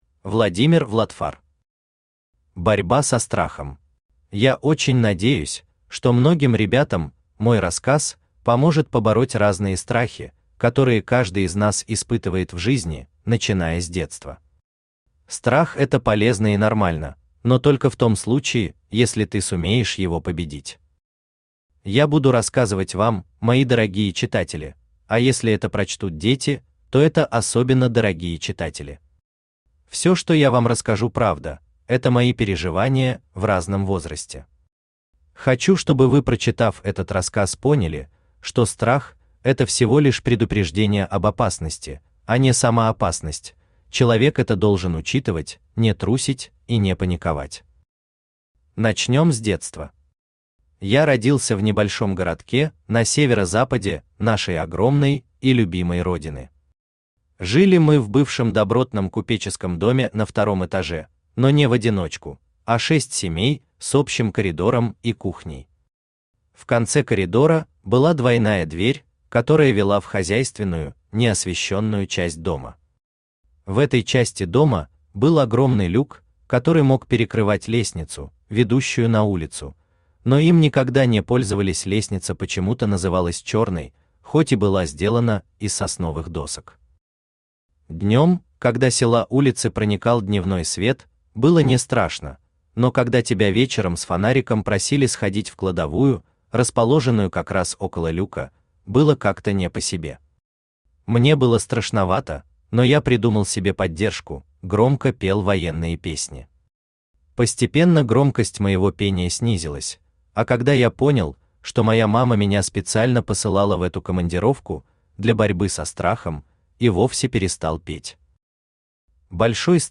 Аудиокнига Борьба со страхом.
Читает аудиокнигу Авточтец ЛитРес.